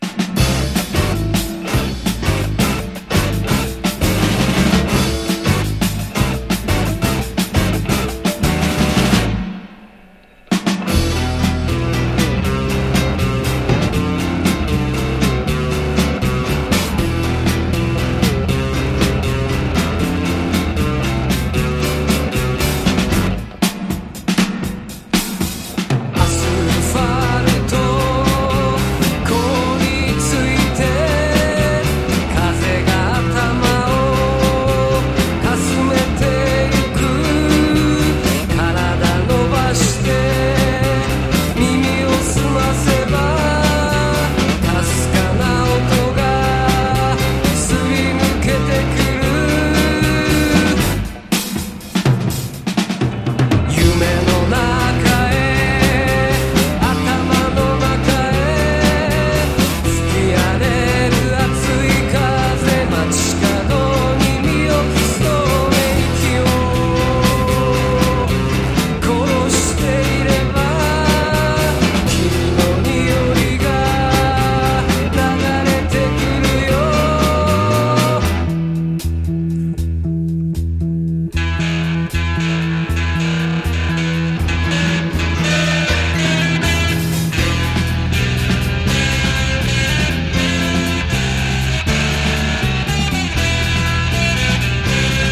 NO WAVE / POST PUNK、東京ロッカーズ、あぶらだこ、ゆらゆら帝国好きまで必聴！